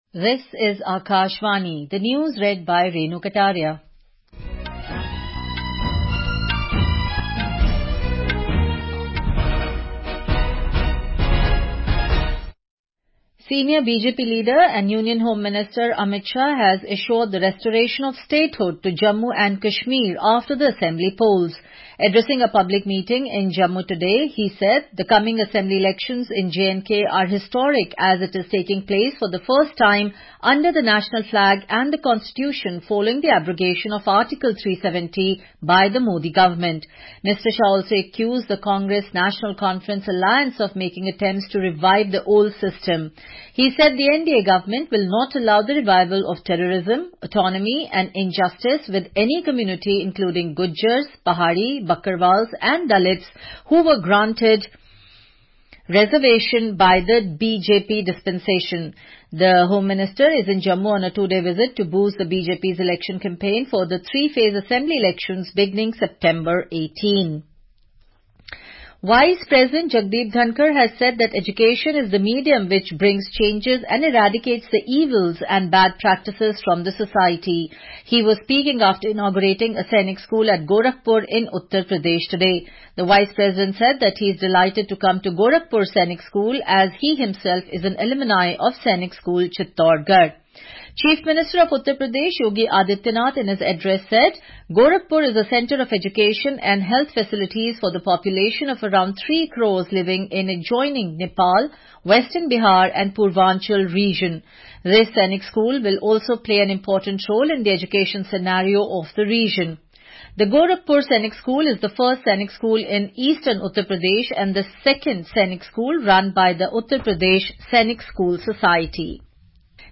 National Bulletins